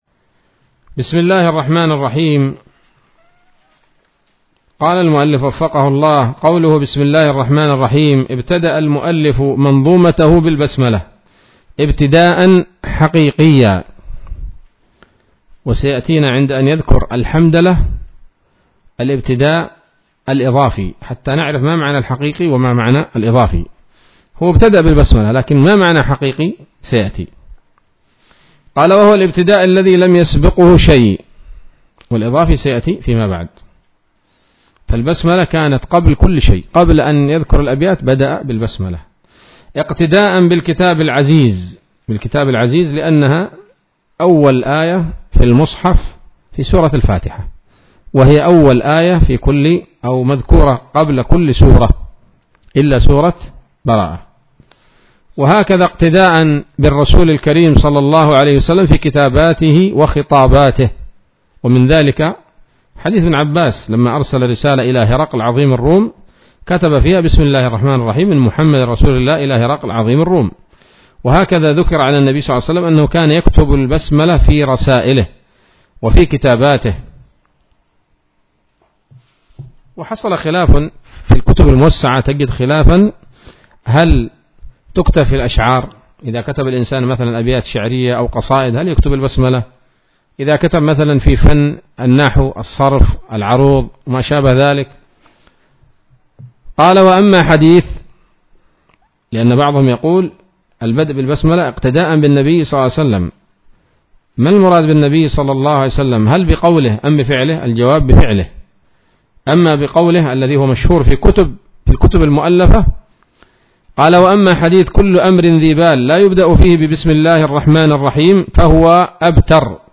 الدرس الرابع من الفتوحات القيومية في شرح البيقونية [1444هـ]